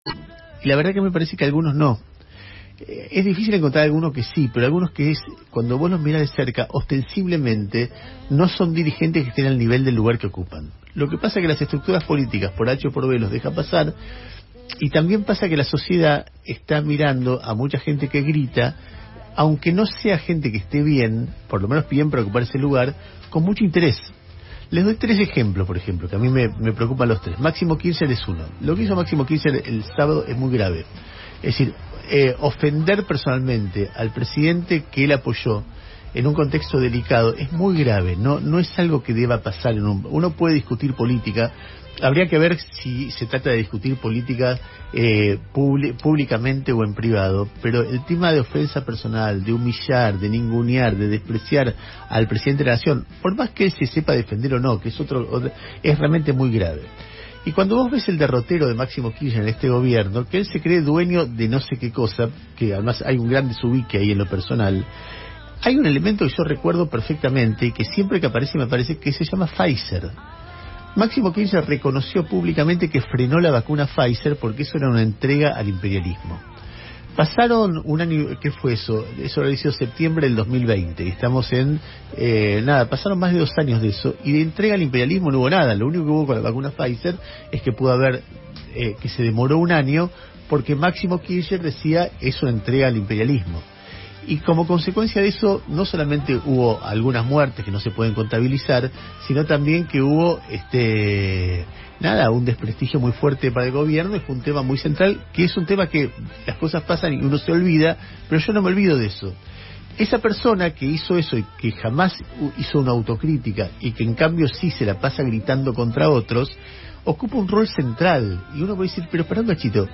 “Lo que hizo Máximo Kirchner es muy grave: ofender personalmente al Presidente que él apoyó es muy grave, no es algo de deba pasar; ofender, humillar y ningunear al presidente la nación, y cuando ves el derrotero de Máximo en este Gobierno, ves que él se cree dueño de no sé que cosa, hay un gran desubique en lo personal”, consideró el conductor del programa de Radio con Vos.